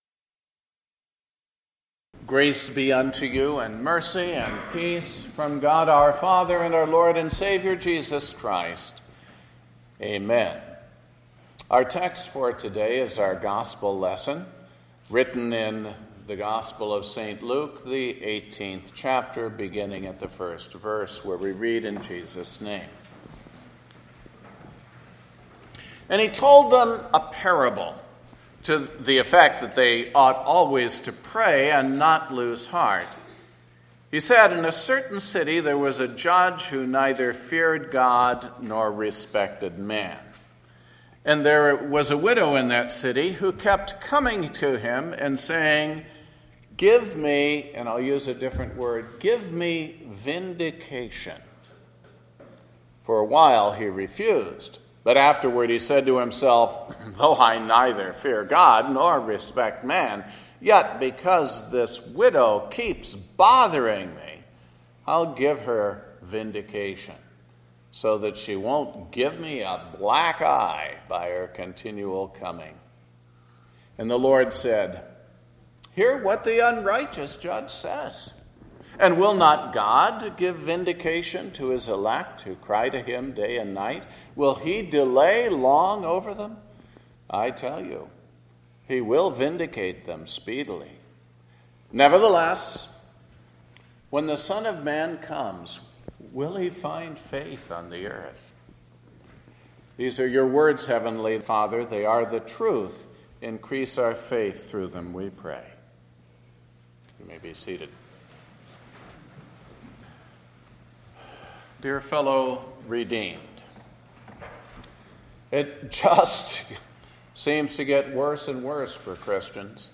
The Twenty-Second Sunday After Pentecost – Sermon based on Luke 18:1-8